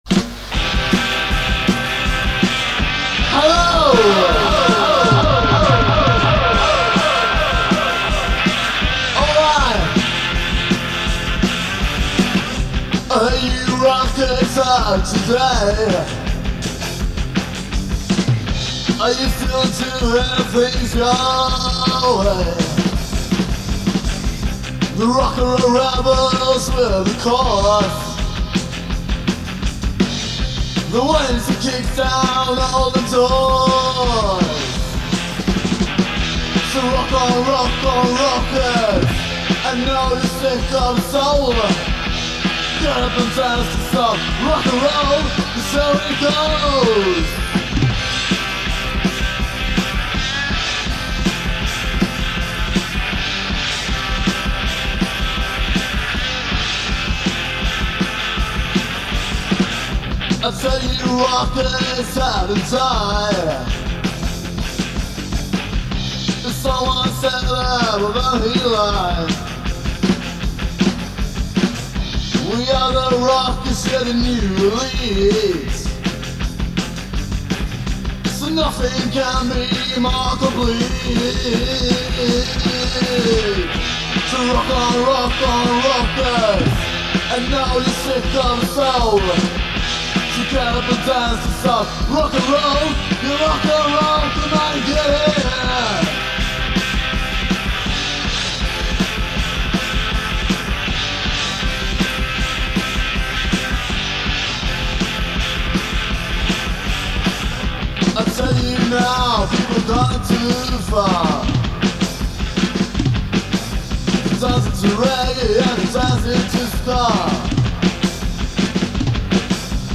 Gitarr
Sång
Trummor
Demo